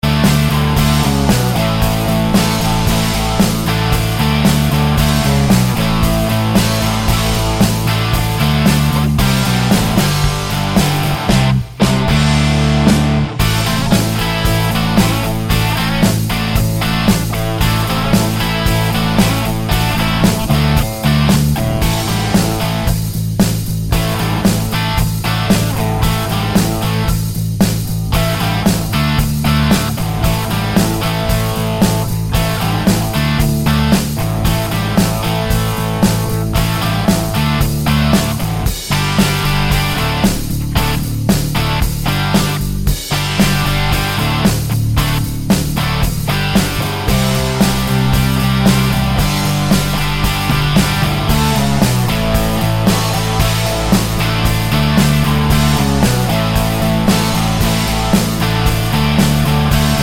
no Backing Vocals Rock 3:03 Buy £1.50